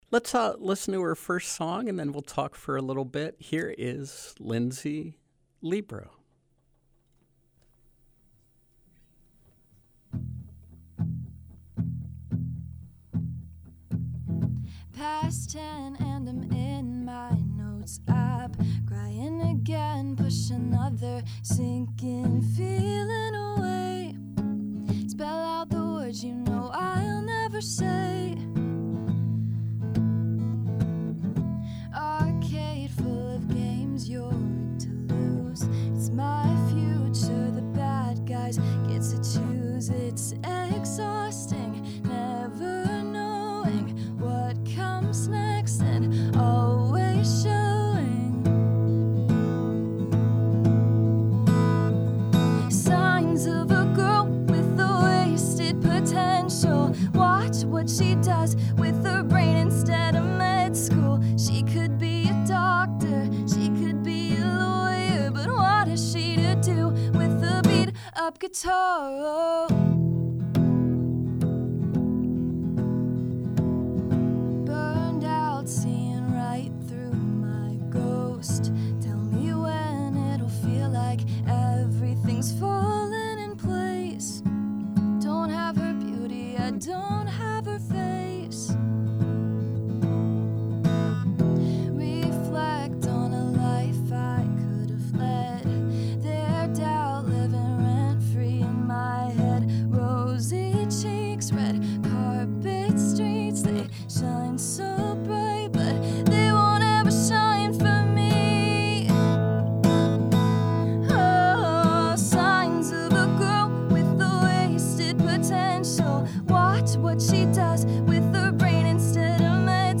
Live music and conversation with singer-songwriter